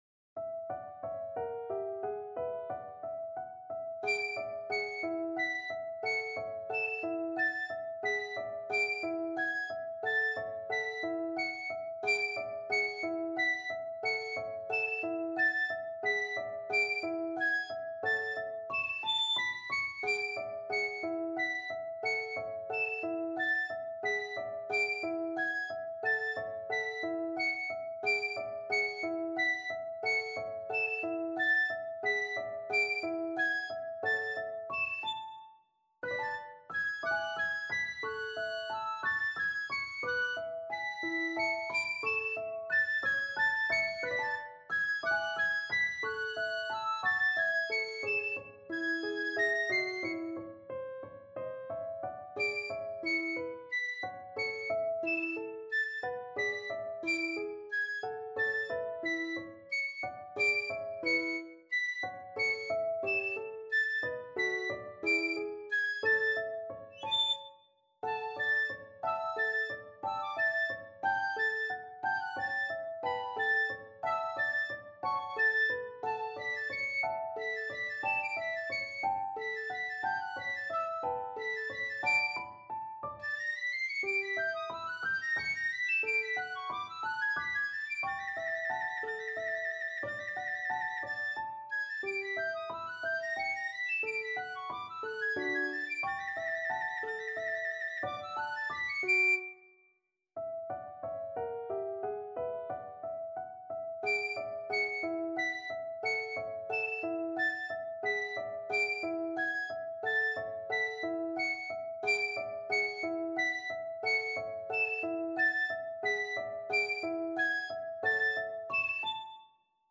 arranged for piccolo and piano